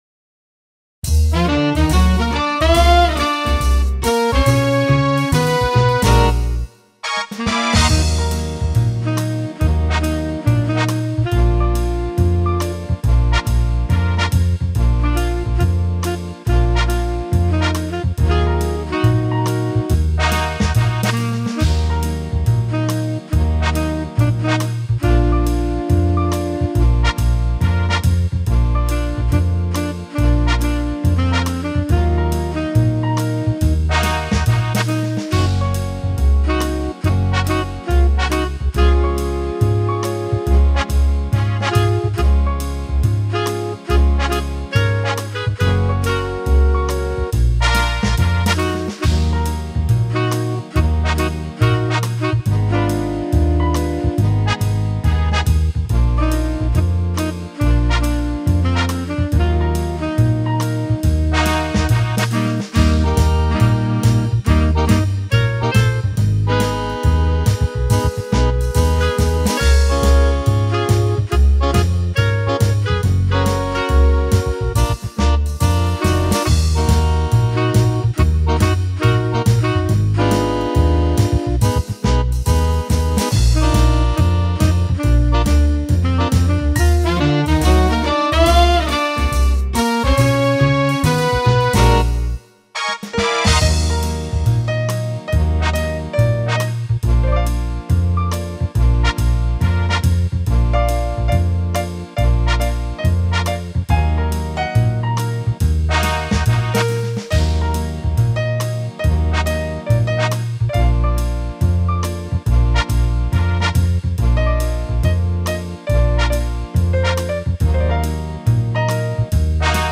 Это просто из опыта освоения синтезатора...